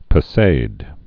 (pə-sād, -zäd)